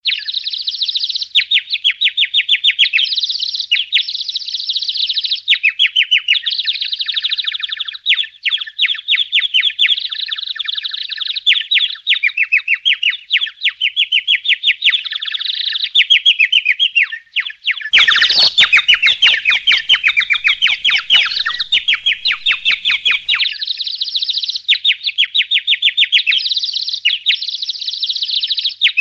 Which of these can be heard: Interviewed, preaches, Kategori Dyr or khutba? Kategori Dyr